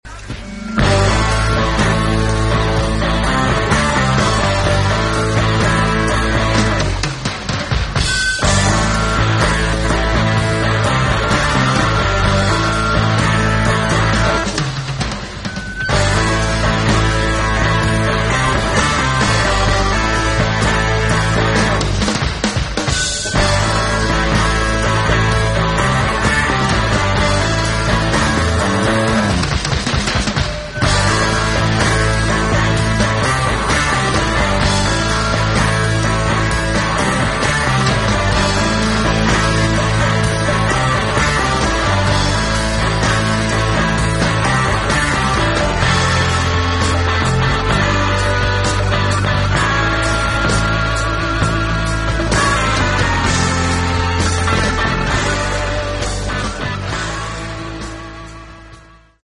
blues-rock band our country has ever known